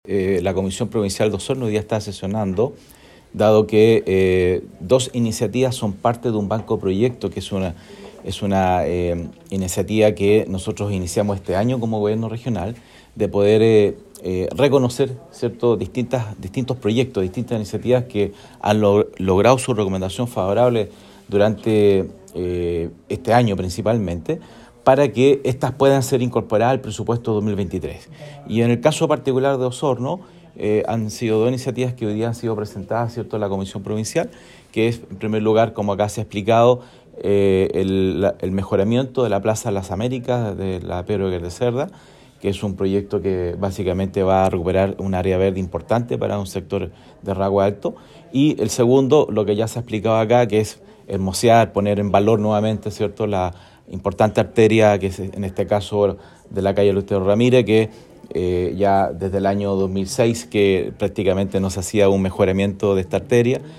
El Presidente de la Comisión Provincial, José Luis Muñoz señaló que esta iniciativa es parte del banco de proyectos que han recibido su resolución satisfactoria durante este año.